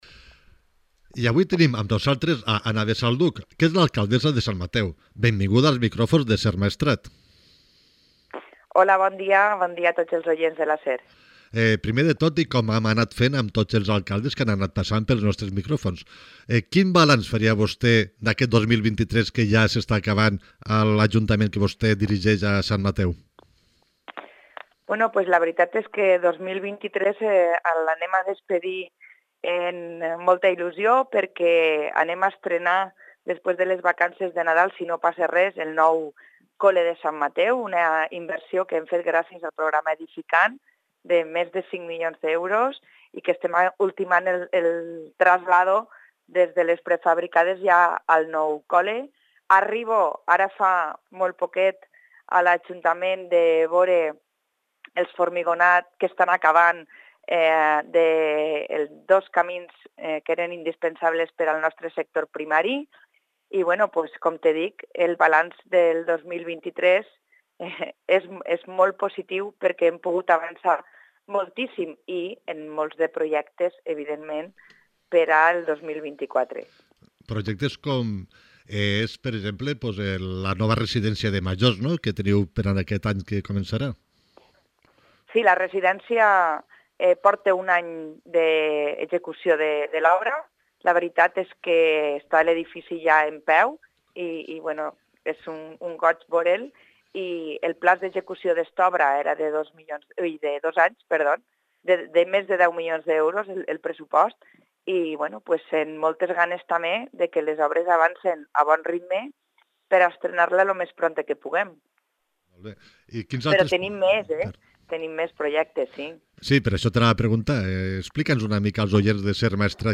Entrevista a Ana Besalduch, alcaldessa de Sant Mateu